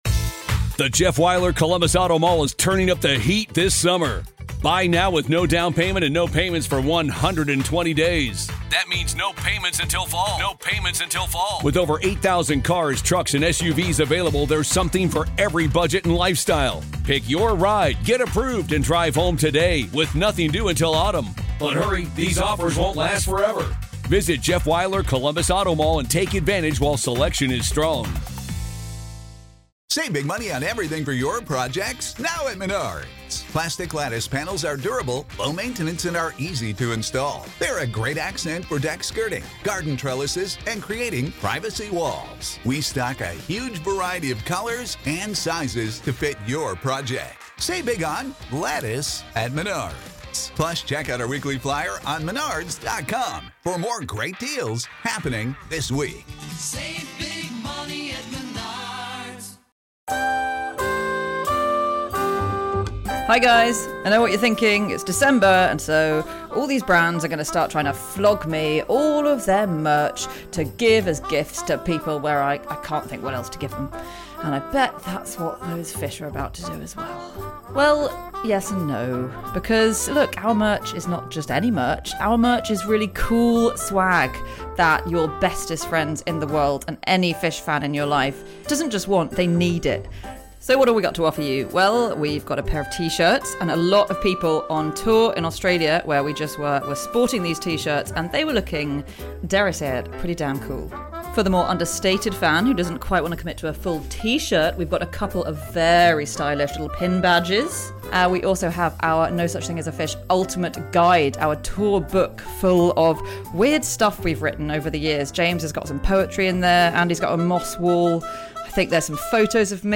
Live from the Sydney Opera House